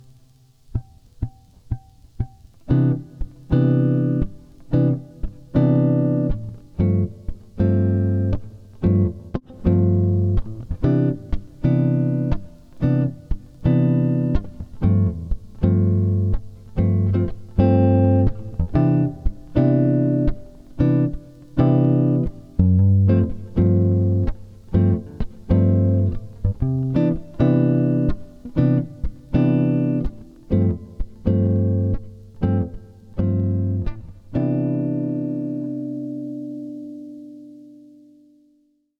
Basic backing chord tracks are below.
C Major Backing medium tempo (1.19 MB)